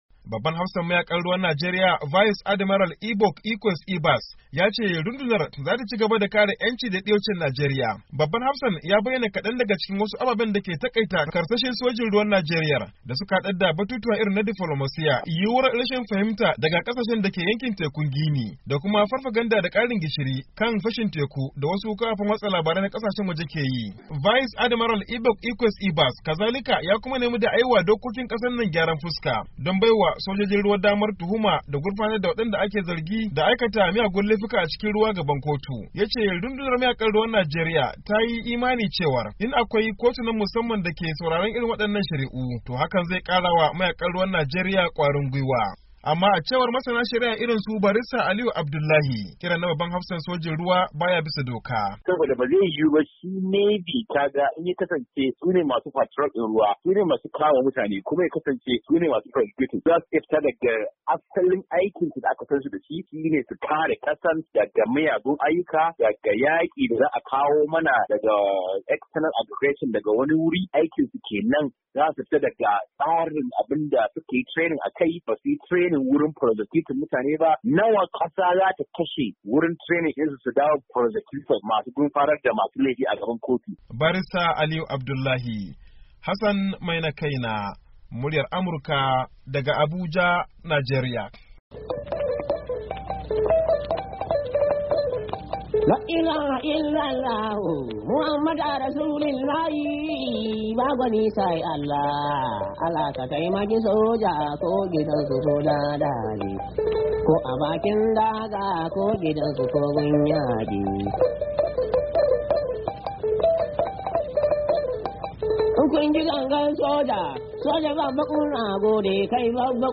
Rahoto Kan aikin rundunar mayakan ruwan Najeriya-2"13